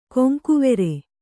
♪ koŋkuvere